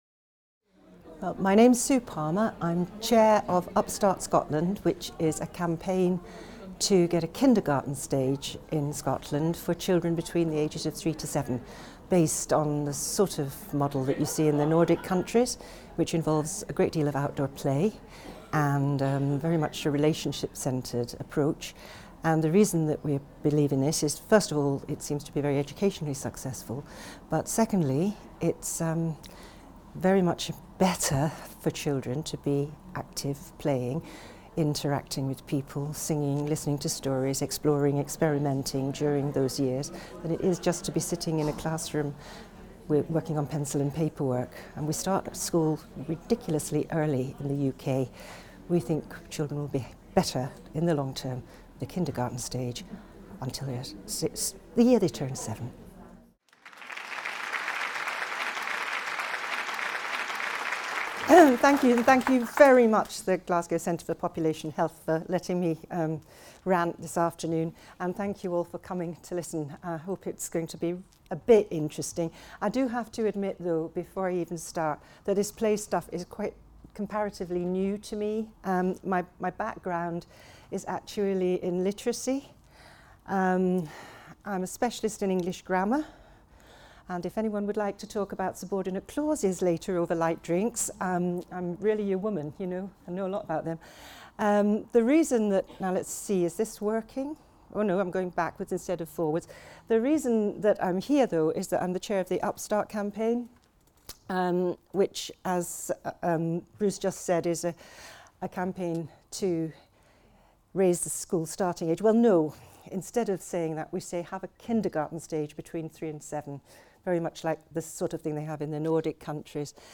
GCPH Seminar Series 2017-2018, Lecture 3: 21st Century Children - the State of Play.